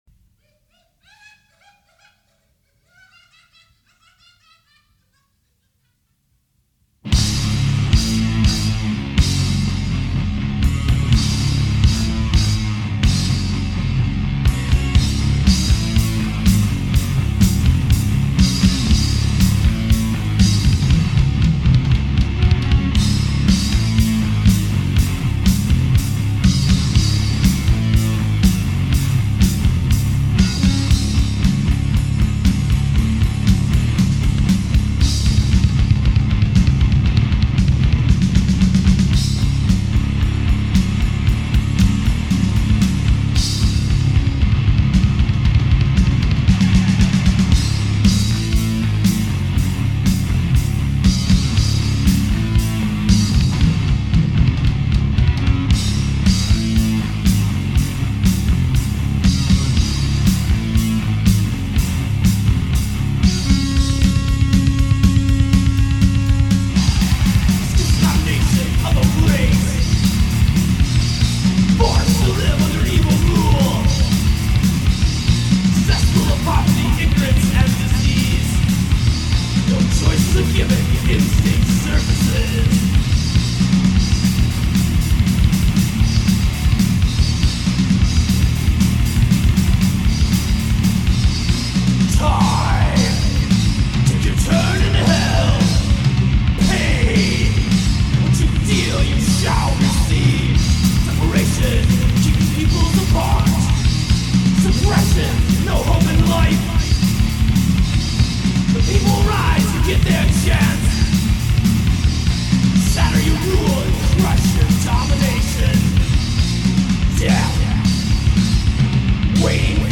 punk band